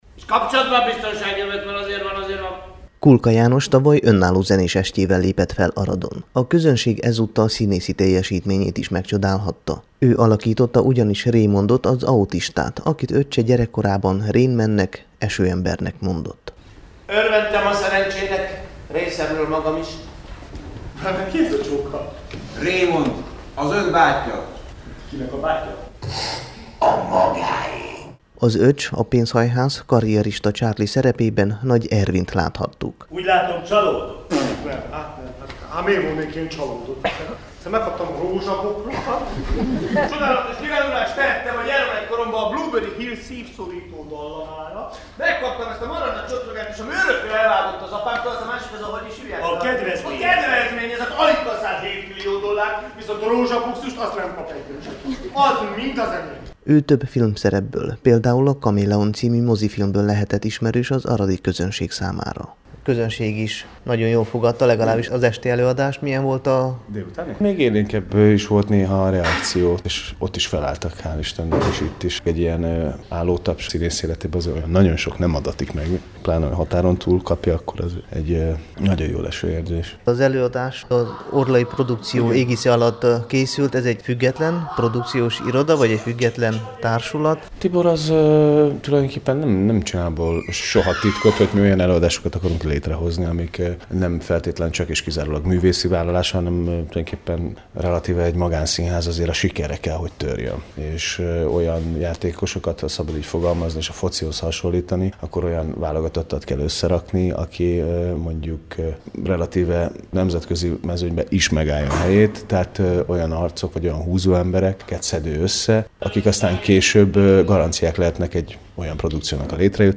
az esti előadásról készített összeállítást a Temesvári Rádió számára